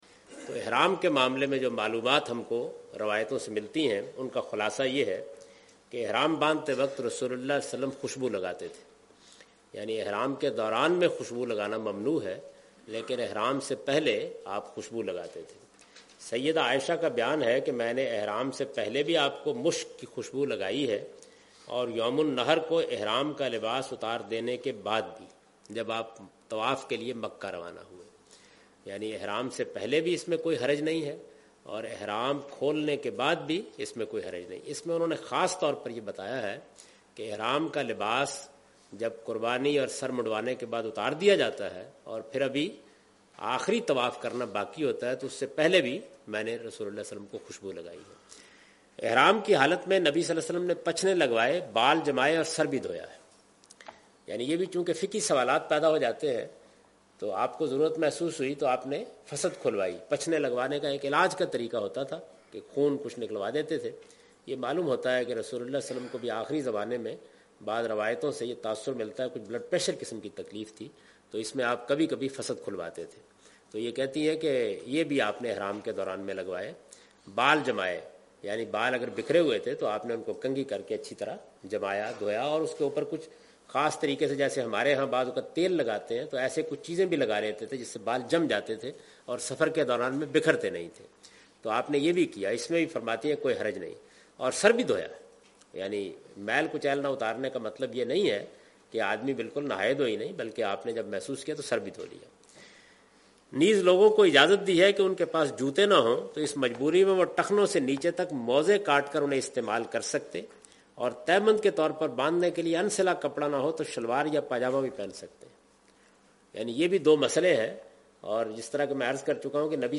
In this video of Hajj and Umrah, Javed Ahmed Ghamdi is talking about "Permissible Actions while Wearing Ihram".
حج و عمرہ کی اس ویڈیو میں جناب جاوید احمد صاحب غامدی "حالت احرام میں جائز اعمال" سے متعلق گفتگو کر رہے ہیں۔